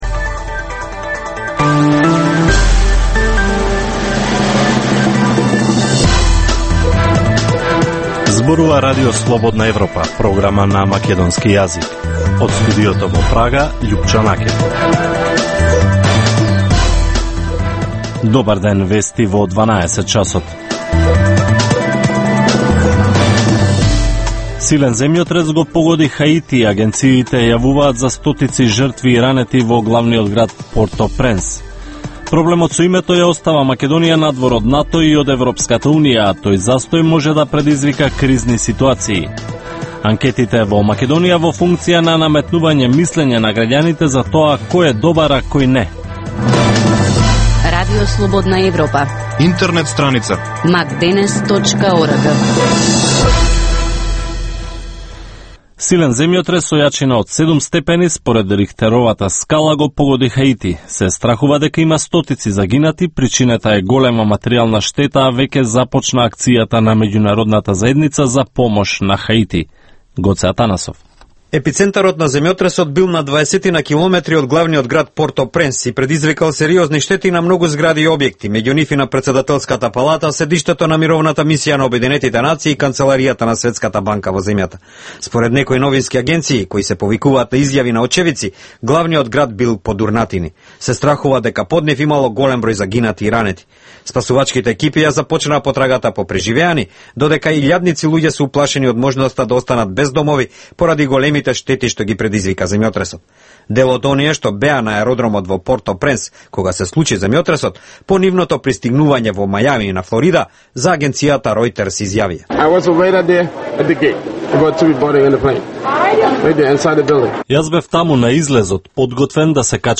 Информативна емисија, секој ден од Студиото во Прага. Топ вести, теми и анализи од Македонија, регионот и светот. Во Вестите во 12 часот доминантни се актуелните теми од политиката и економијата.